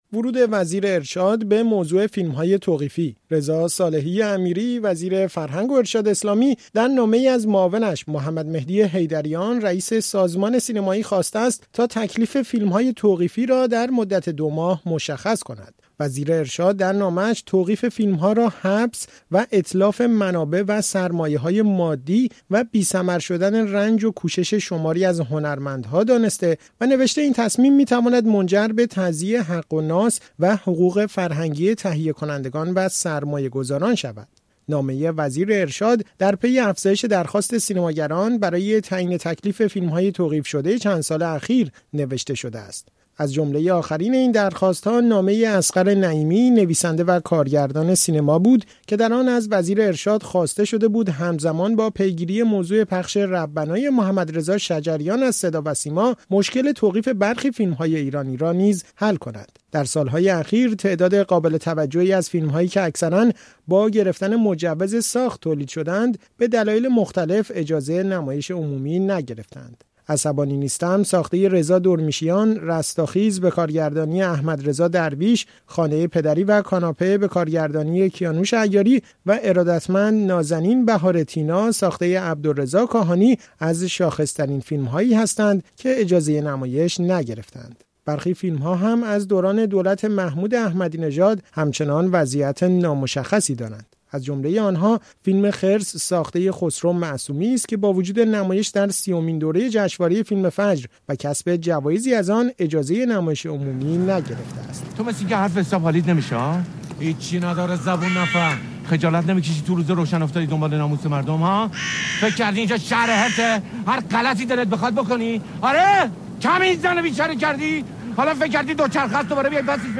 در گفت و گو با رادیو فردا